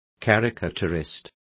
Complete with pronunciation of the translation of caricaturists.